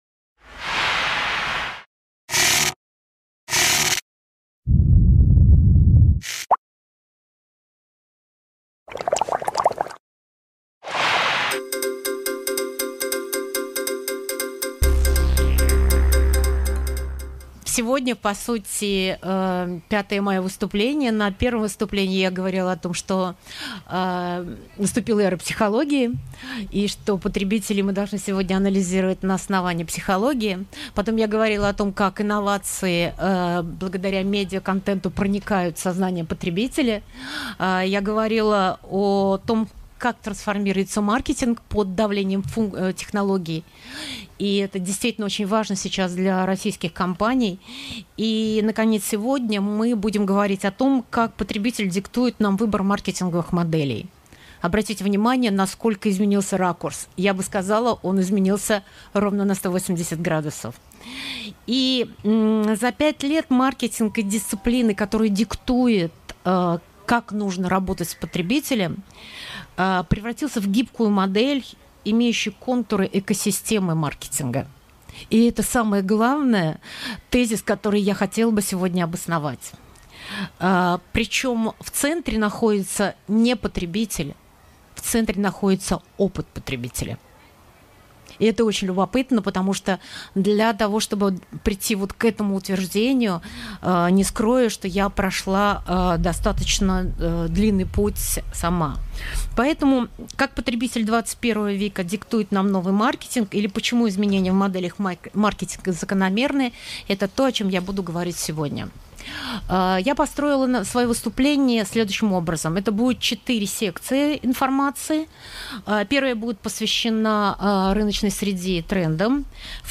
Аудиокнига Как потребитель XXI века диктует нам новый маркетинг | Библиотека аудиокниг